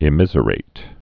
(ĭ-mĭzə-rāt)